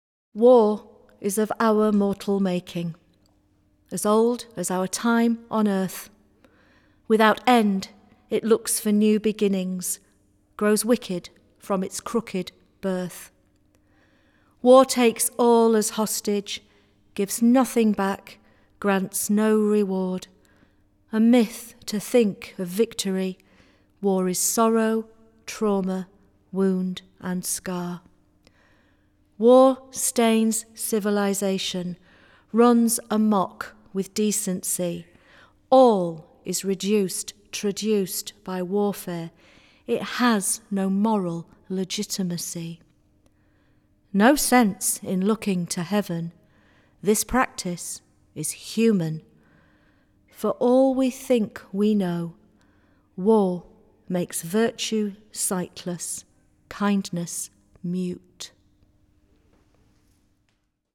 Recorded at Craxton Studios, May 12, 2019
Jazz and poetry commemorating the end of The First World War